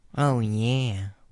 描述：我用书呆子的声音说哦耶。